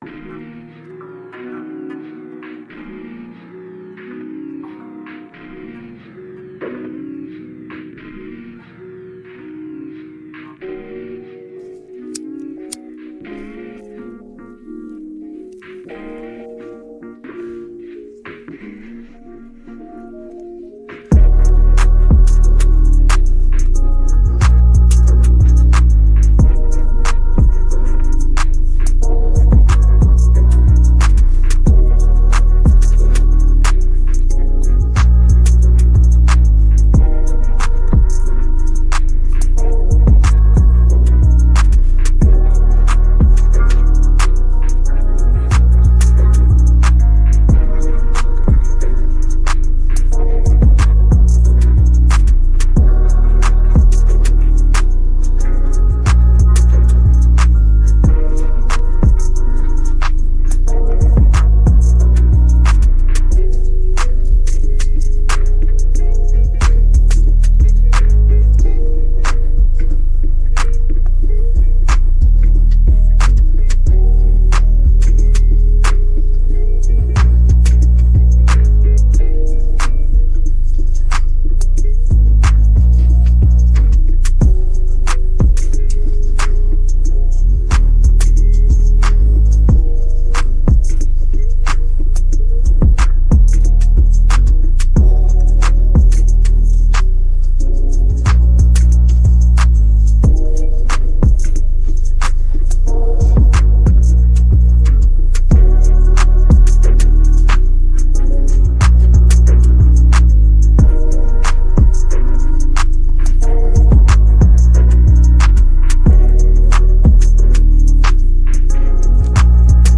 Hip hophiphop trap beats